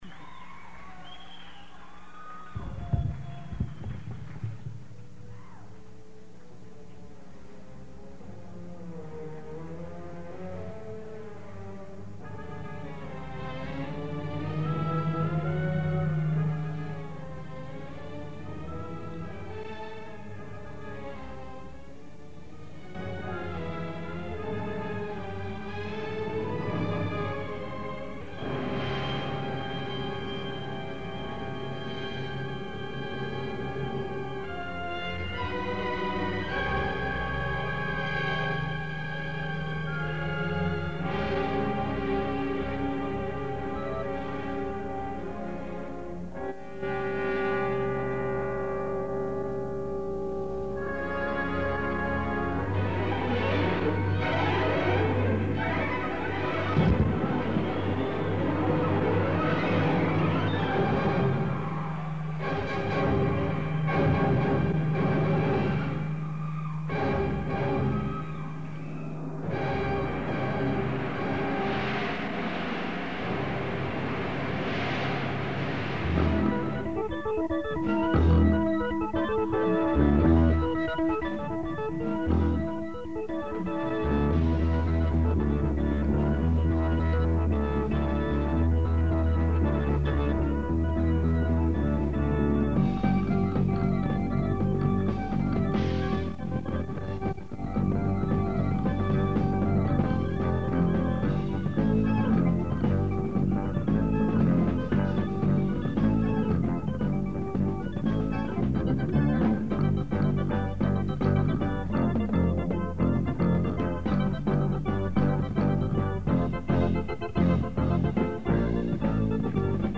The audience of mostly teenagers loved it.